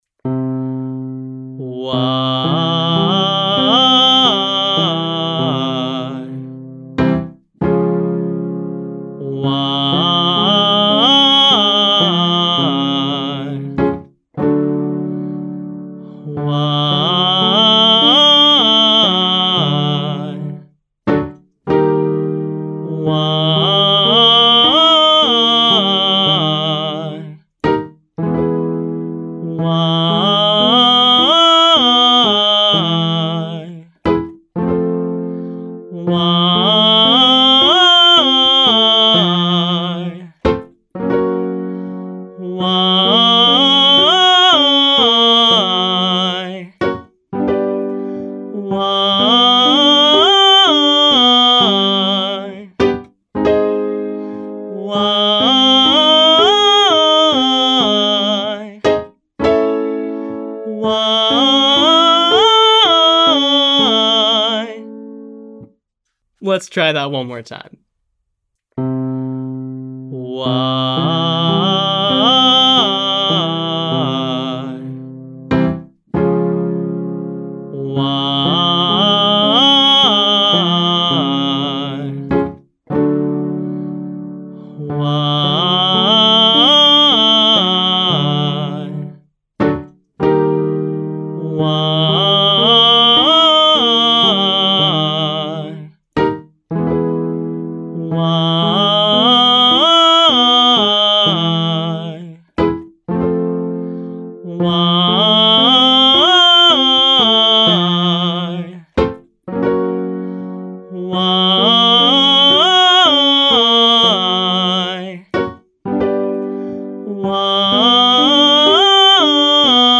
Pop Daily Exercise Lesson 5C